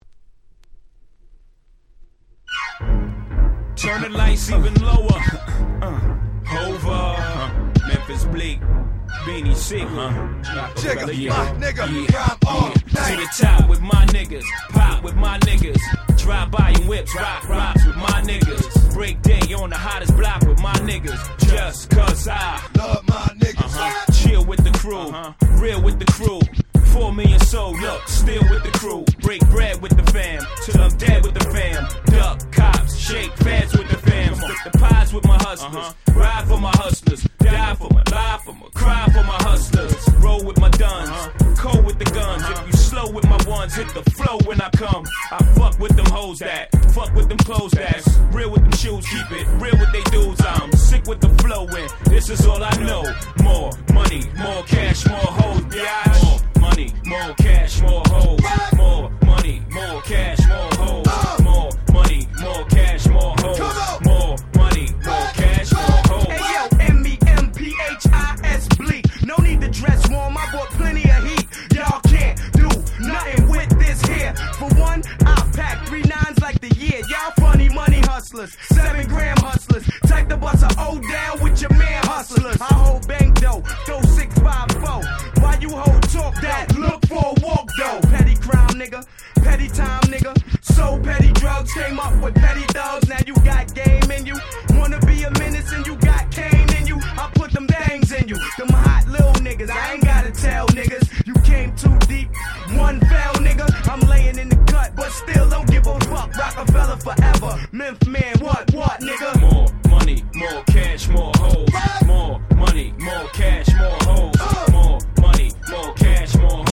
99' Smash Hit Hip Hop !!
Club Hit !!